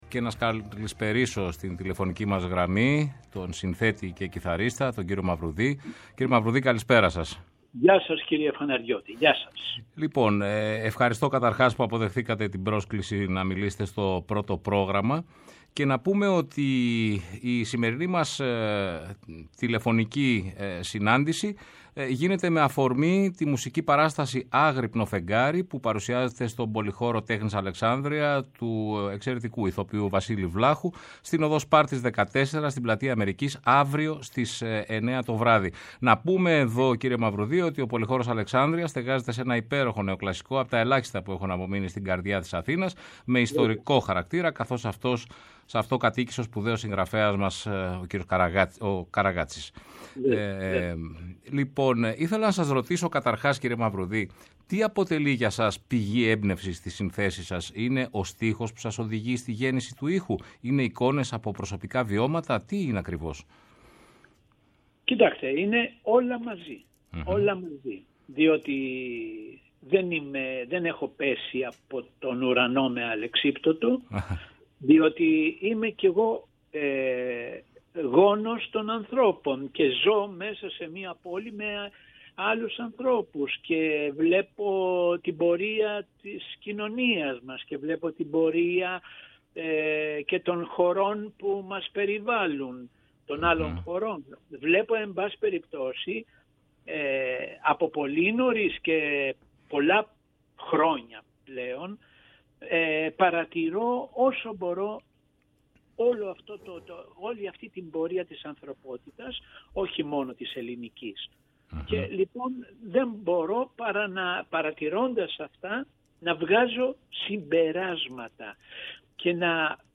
Η τελευταία συνέντευξη του Νότη Μαυρουδή στο Πρώτο Πρόγραμμα της Ελληνικής Ραδιοφωνίας
Ο αδικοχαμένος σπουδαίος κιθαριστής και μουσικοσυνθέτης Νότης Μαυρουδής μιλά για το σύνολο της μουσικής του διαδρομής και την μεγάλη αγάπη του για τους Έλληνες ποιητές και την ελληνική γλώσσα. Παράλληλα αναφέρεται στις πηγές της έμπνευσής του για τη δημιουργία του μουσικού του έργου, αλλά και στην πορεία της ελληνικής κοινωνίας τις τελευταίες δεκαετίες.